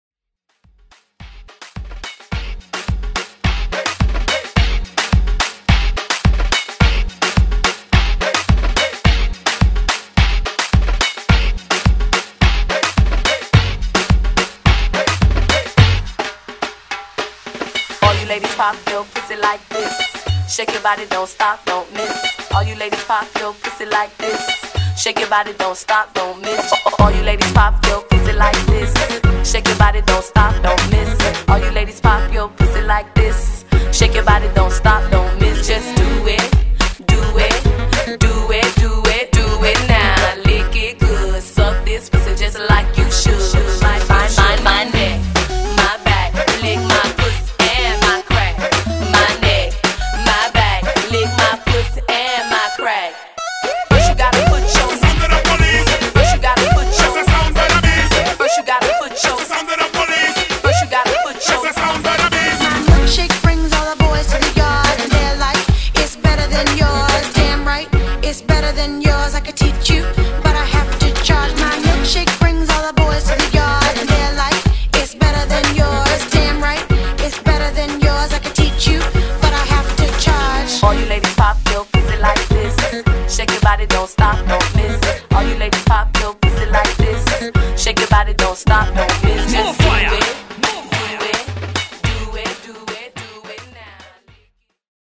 Genres: HIPHOP , MOOMBAHTON , PARTY BREAKS
Dirty BPM: 107 Time